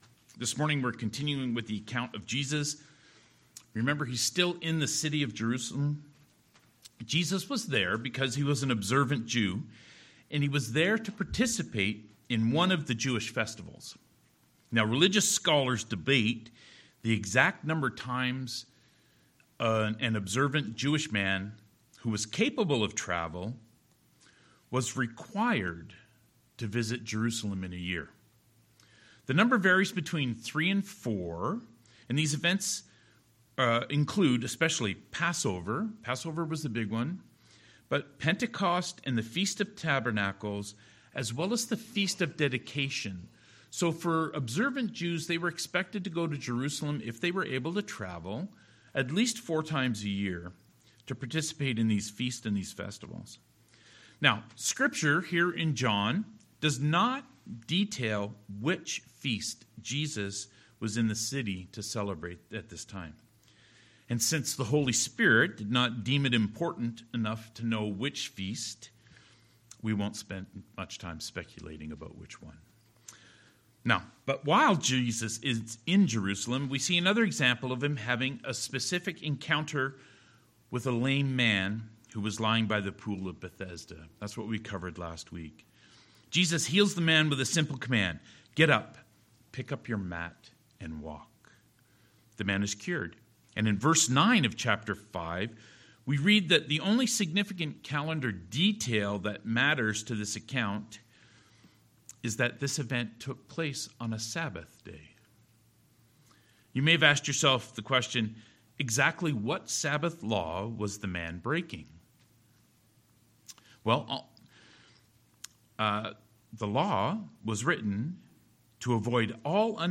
John "So That You May Believe" Passage: John 5: 16-30 Service Type: Sermons « Does Faith Require Action?